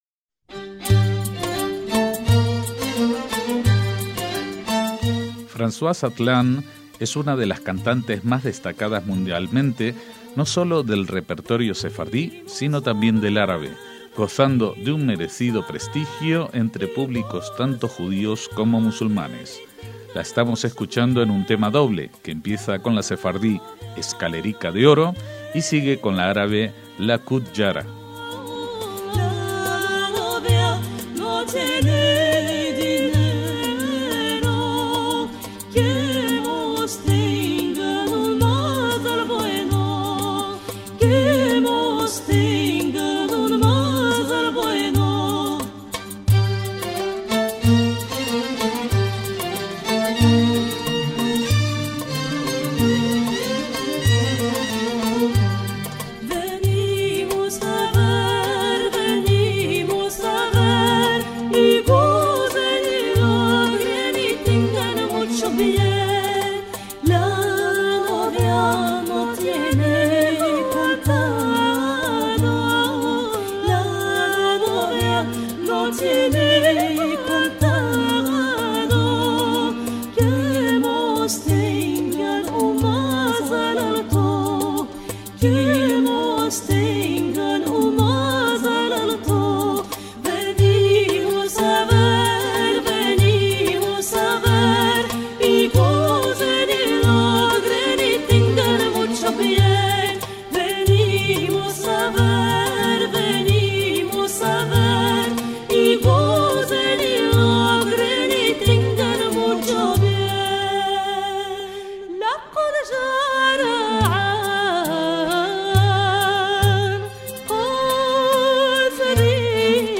MÚSICA SEFARDÍ
es una cantante francesa